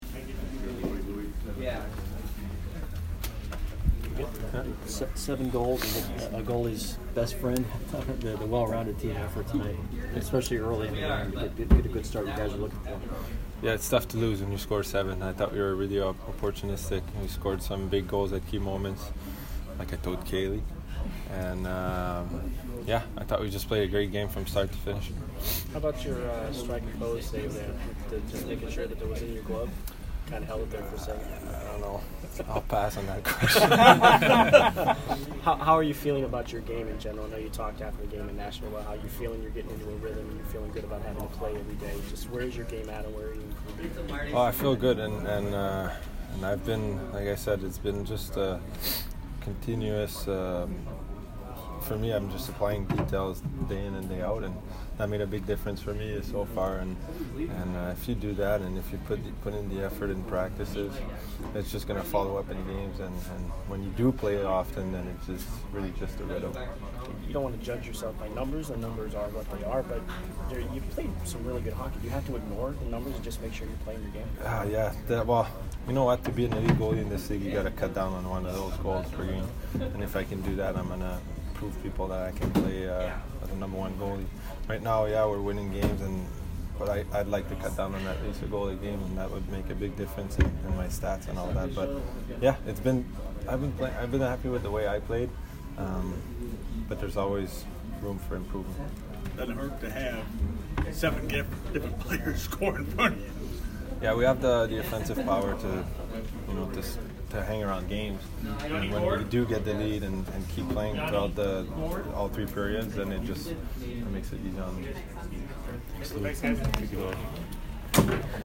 Louis Domingue post-game 11/21